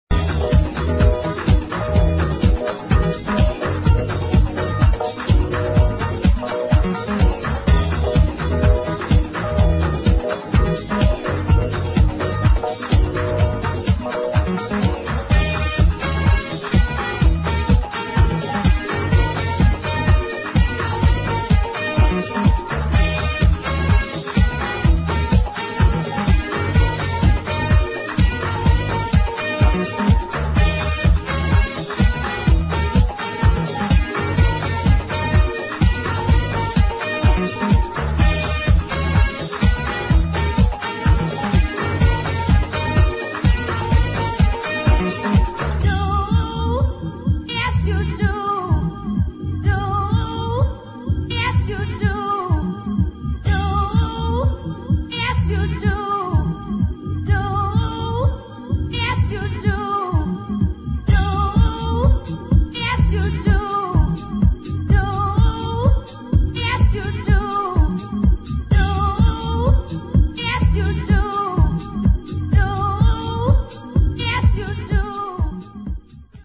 [ HOUSE ]